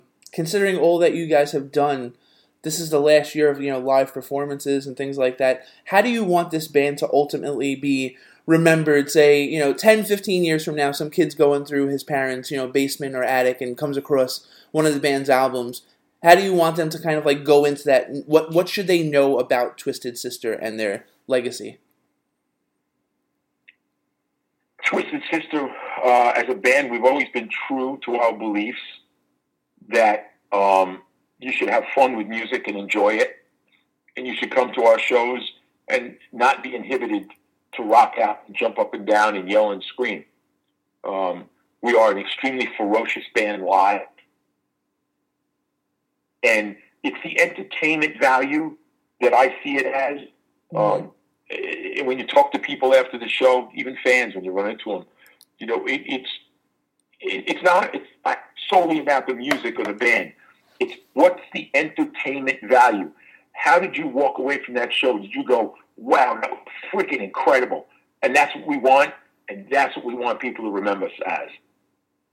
Review Fix chats with Twisted Sister bassist Mark Mendoza, who discusses theÂ band’s legacy after 40 years of performances, culminating with their Metal Meltdown concert and documentary release.